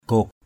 /ɡ͡ɣo:k/ (d.) hốc, hang = caverne. cave. gok patuw _gK bt~| hang đá = grotte.